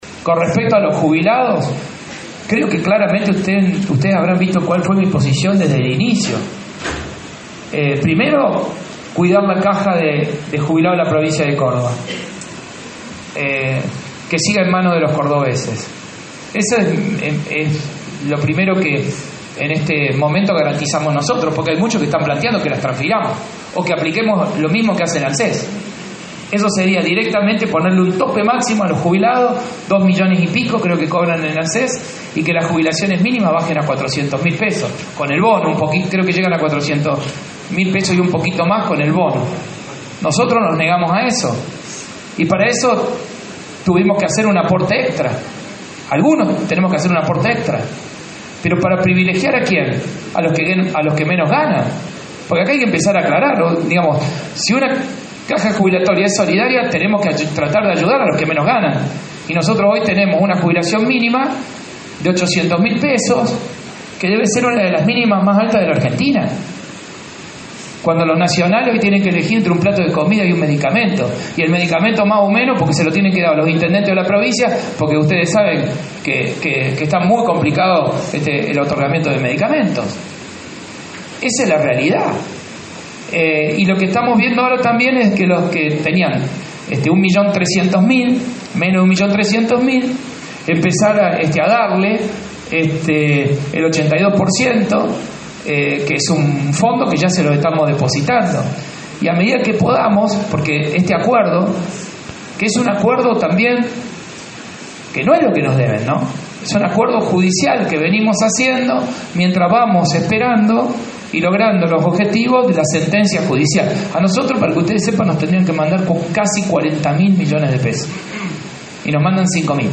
El mandatario provincial encabezó una conferencia de prensa en la que se refirió al conflicto docente por el reclamo salarial.